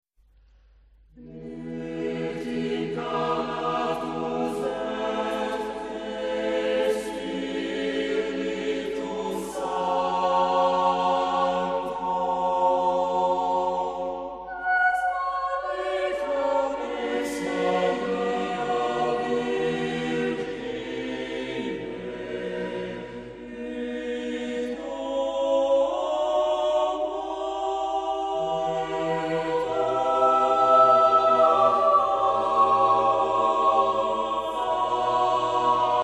Genre-Style-Form: Motet ; Sacred
Type of Choir: SATB  (4 mixed voices )
Tonality: E minor